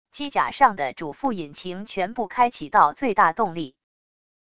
机甲上的主副引擎全部开启到最大动力wav音频